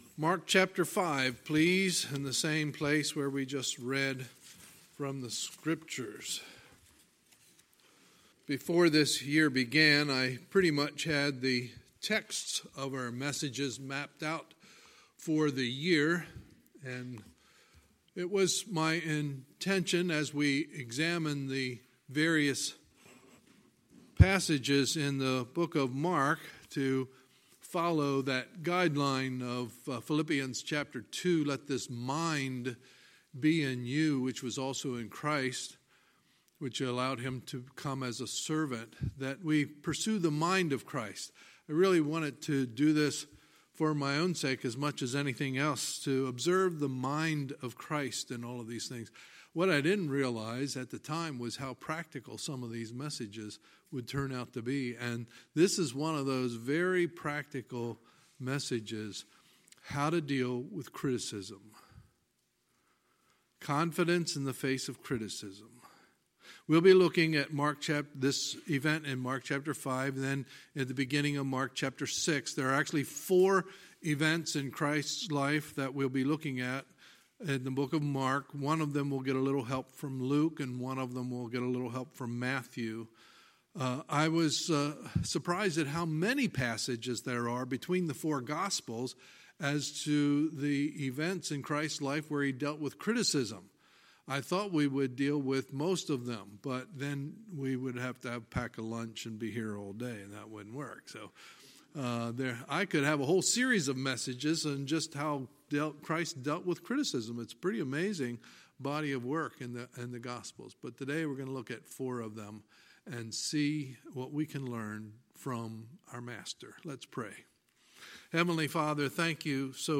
Sunday, May 19, 2019 – Sunday Morning Service
Sermons